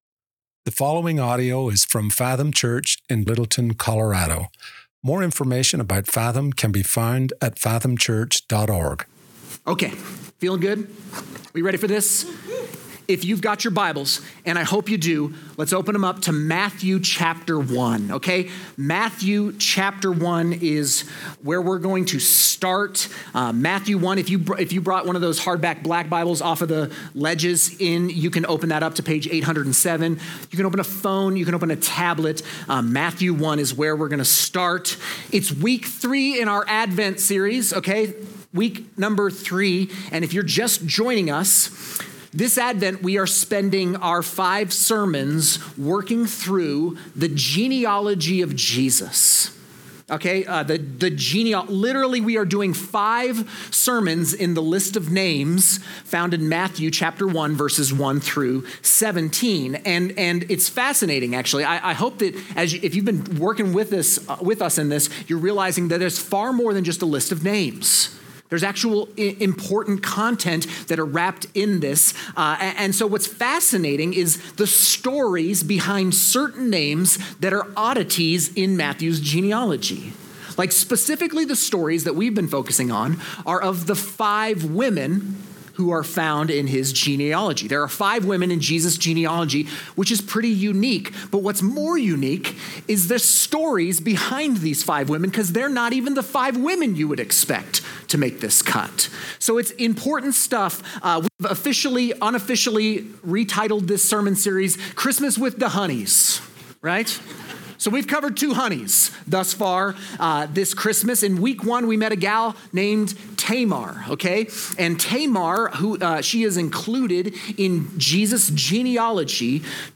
Fathom Church Sermons